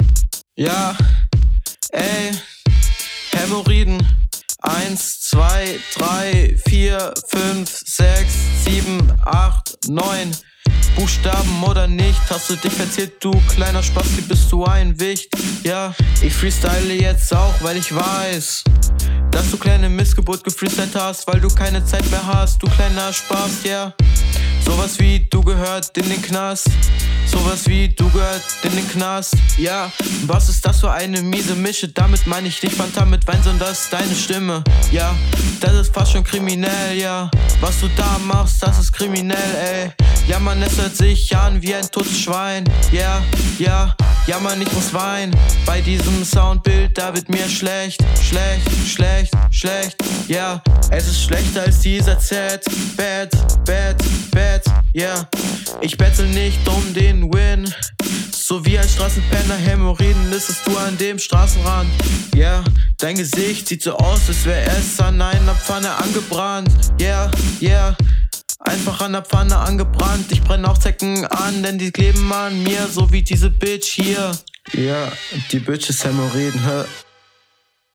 Flowlich besser.
Du kommst hier am coolsten auf den Beat.
hört sich schön mix & mastert an, die Lines gefallen mir auch!